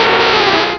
Cri de Nidoqueen dans Pokémon Rubis et Saphir.